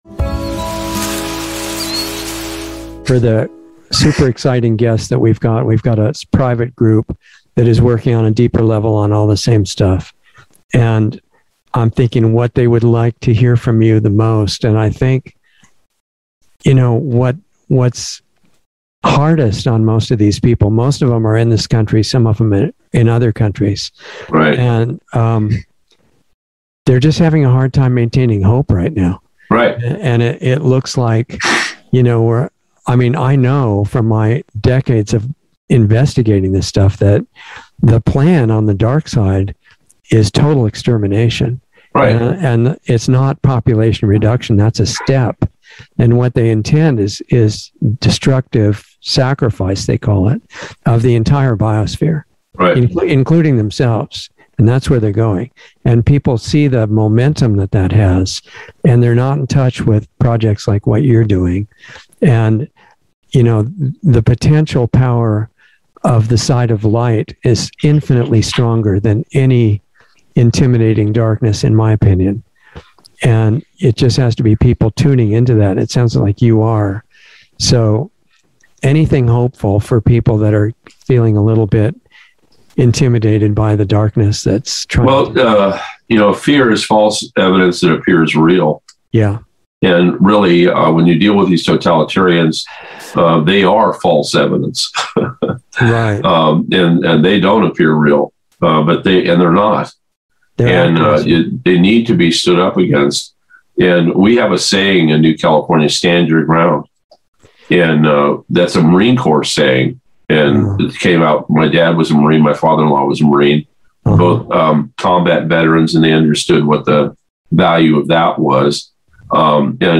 Insider Interview 10/15/21